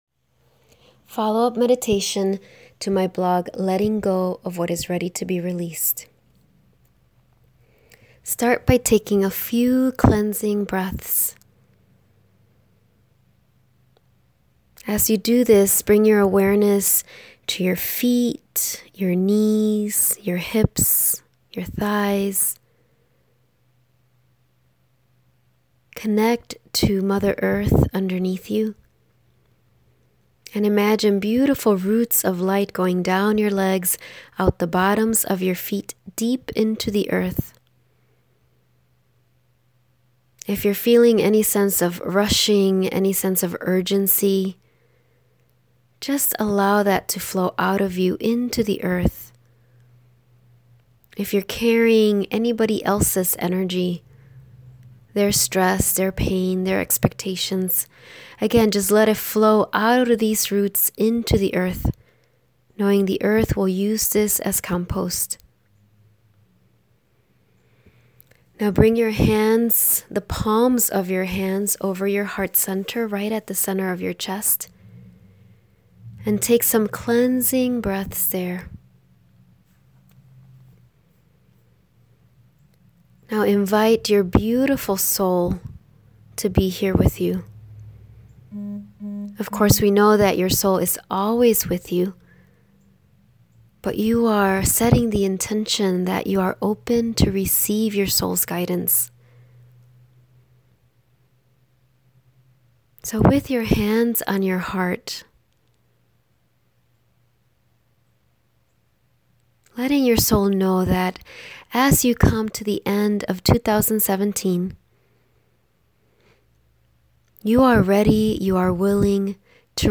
Follow-Up Meditation